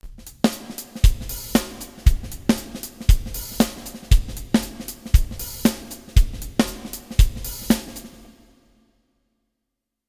Gerade bei den Halleffekten ist die Auswahl groß, neben der ganzen Palette von kleinen Raumsimulationen mit dominanten Erstreflexionen bis hin zu riesigen Hallräumen sind auch Platten-, Feder- und Bandhall integriert.
FX Plate
alto_zephyr_zmx124fx_usb_testbericht_03_fx_plate.mp3